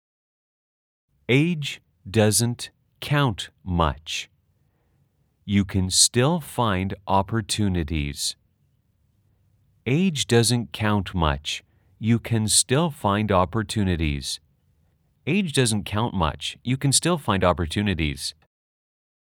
/ 유캔 스띠얼 파인 / 어퍼튜니리이즈 /
(3회 반복 연습:아주 느리게-느리게-빠르게)